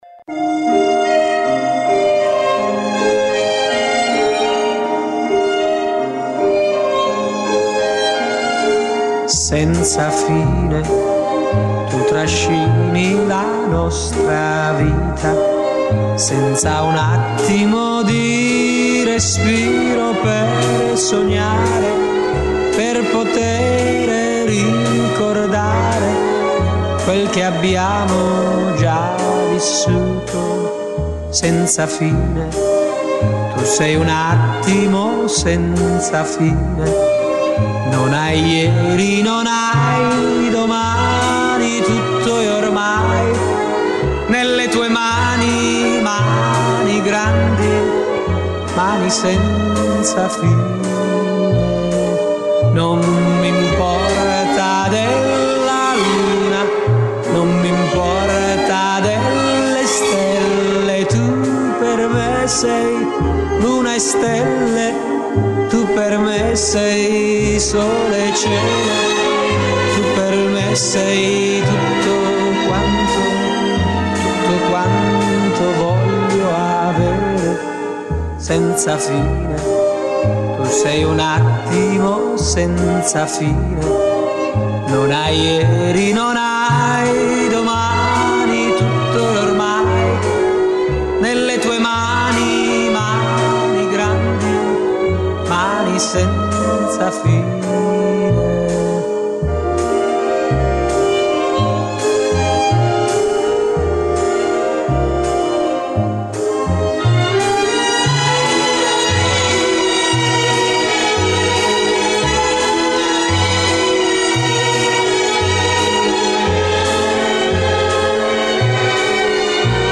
La storia di Gino Paoli raccontata dallo stesso Gino Paoli: in occasione della pubblicazione della sua autobiografia "Cosa farò da grande"...
In questo speciale dedicato a ricordare il grande cantautore, ascolteremo alcuni estratti da quella presentazione, insieme ad alcune delle sue indimenticabili canzoni.